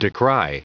Prononciation du mot decry en anglais (fichier audio)